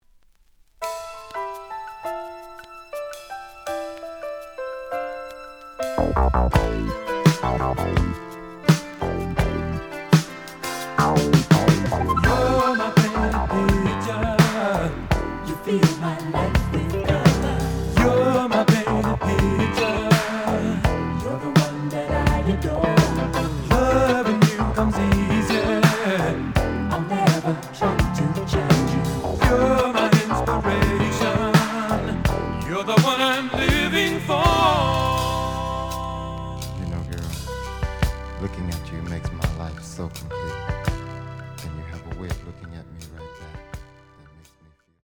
SOUL FUNK
試聴は実際のレコードから録音しています。
●Genre: Funk, 80's / 90's Funk